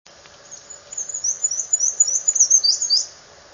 Mount Prospect, Eagles Eye Parking Lot, Lake George, 6/28/02, (14kb), "we-see" song
Given its very high and faint song, it is easiest to observe in late April combing branches for insects just before the leaves have unfolded and prior to the arrival of other much louder birds.